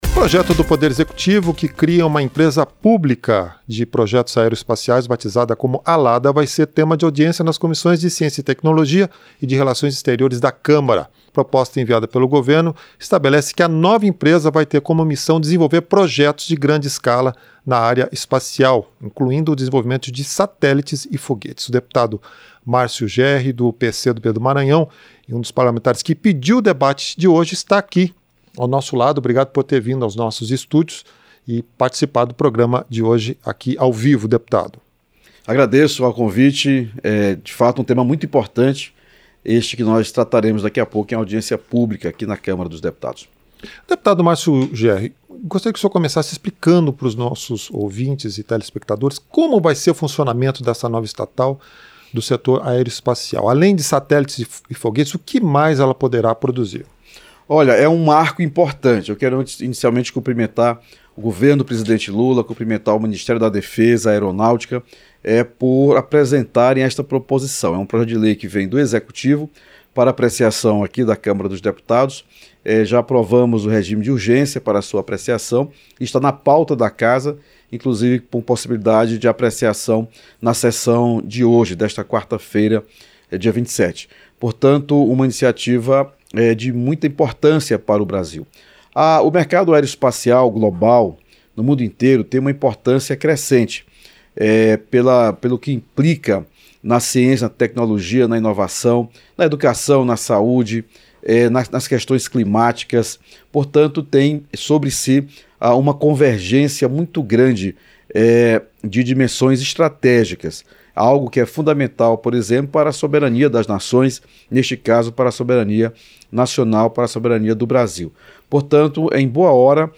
Entrevista - Dep. Márcio Jerry (PCdoB-MA)